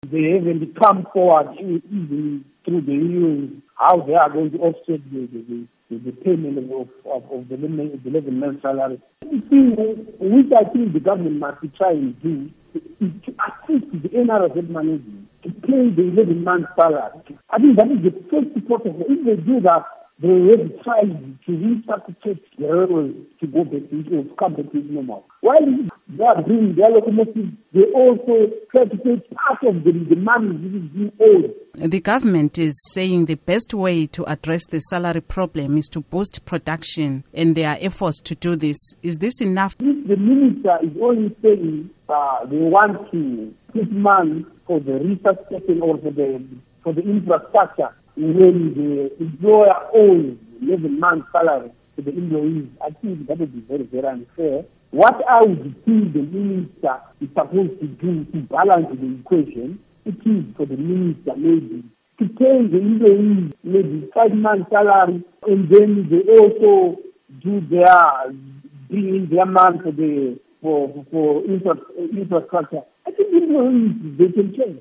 Interview with Obert Mpofu